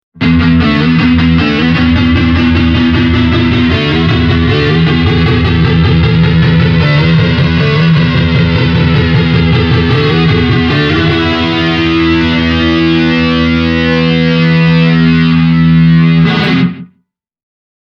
Ääniesimerkit on äänitetty Zoom H1 -tallentimilla.
Myös THR10X:n tapauksessa käytin LP-tyylistä kitaraa:
Yamaha THR10X – Power II + Flanger + Delay